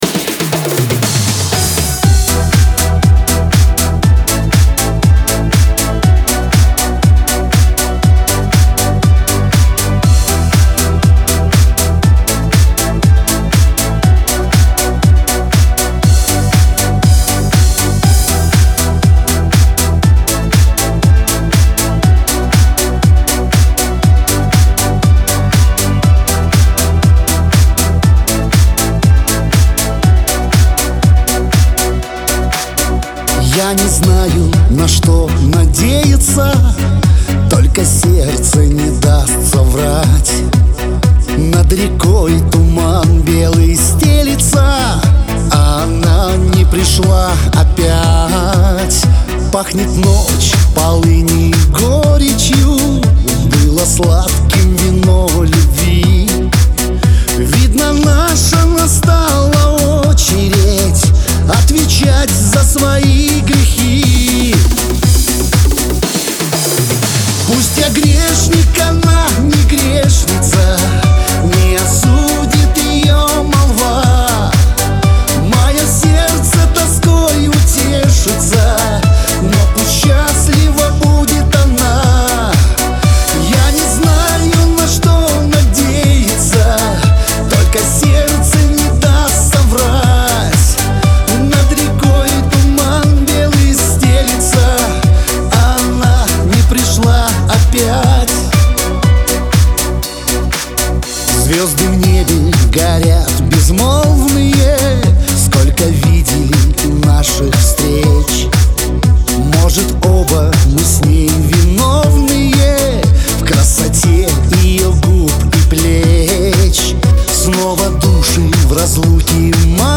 Шансон песни